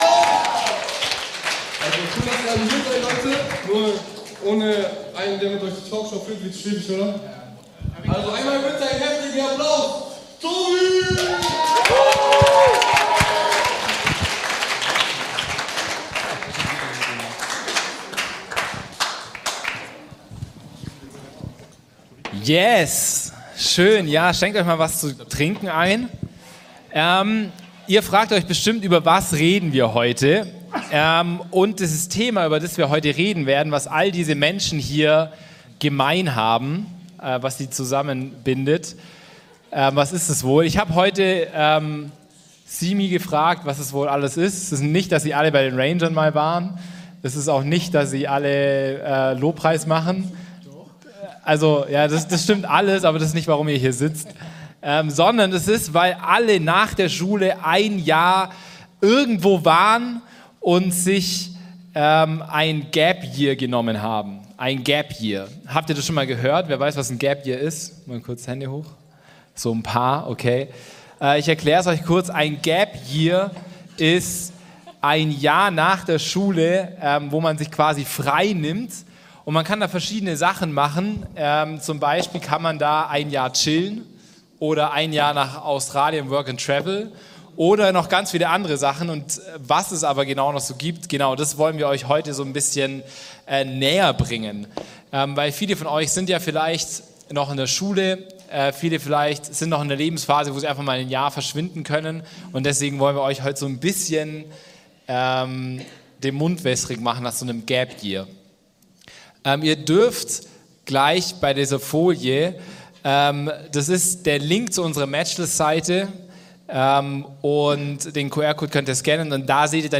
Christliches GAP-Year - Panel Talk